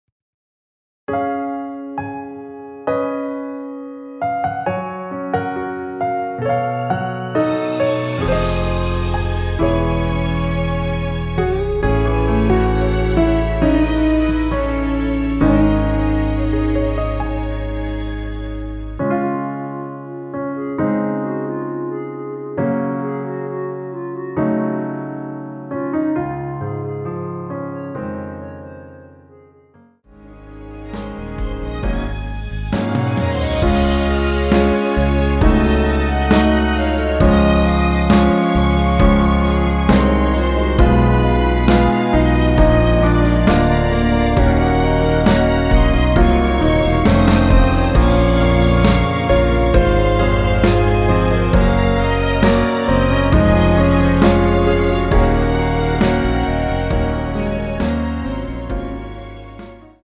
원키에서(-6)내린 멜로디 포함된 MR입니다.(미리듣기 확인)
Db
멜로디 MR이라고 합니다.
앞부분30초, 뒷부분30초씩 편집해서 올려 드리고 있습니다.
중간에 음이 끈어지고 다시 나오는 이유는